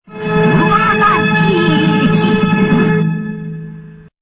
そして声でも玉緒さんがゲームを盛り上げます。
青い文字、またはスピーカーアイコンをクリックするとRealAudioで玉緒さんの声を聞くことができます。